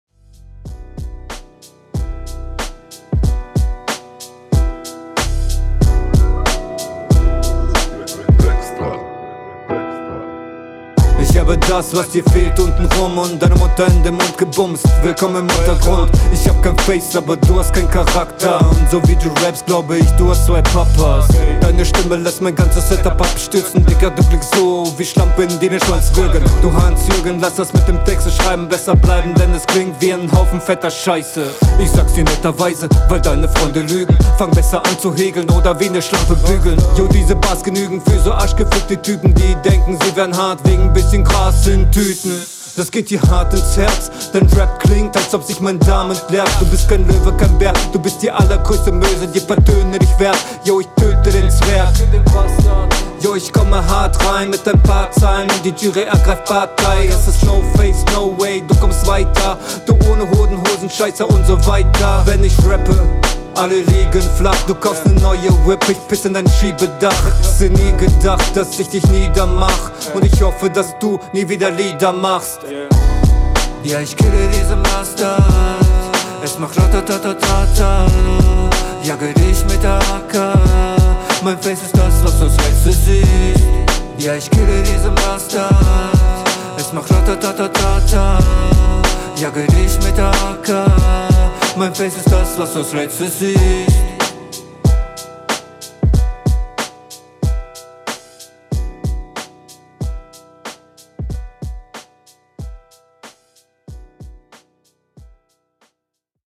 Auf dem Beat kommst du nochmal cooler, das geht gut nach vorne, nur finde ich …